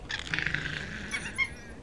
Звуки красного волка
Крики красных волков в момент спаривания